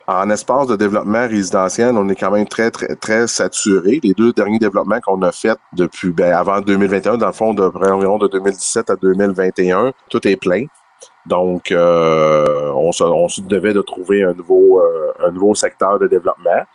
Le maire, Gilles Jr Bédard, a mentionné que la Municipalité avait besoin d’ajouter de nouvelles portes sur son territoire.